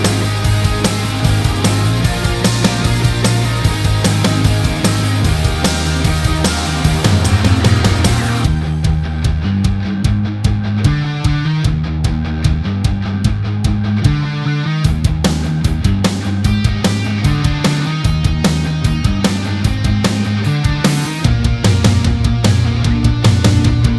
Minus Main Guitar Pop (2010s) 2:49 Buy £1.50